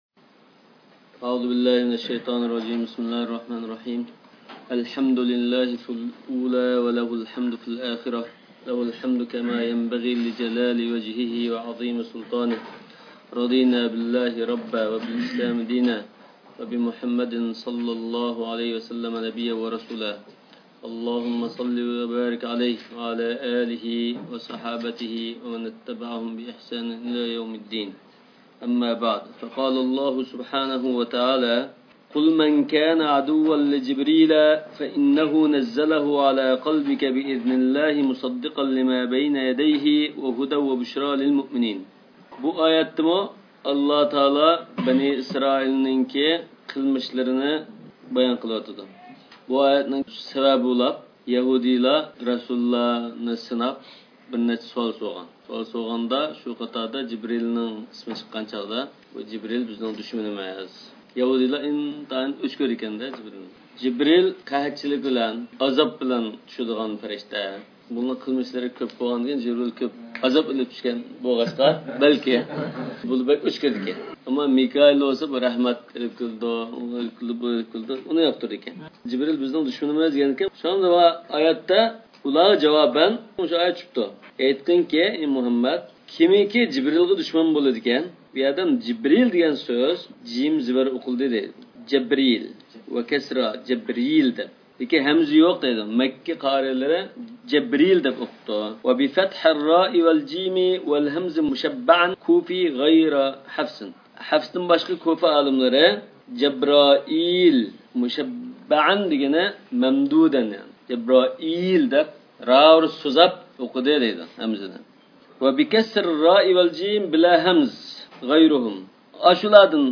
ئاۋازلىق دەرسلەر , تەفسىرۇ نەسەفىي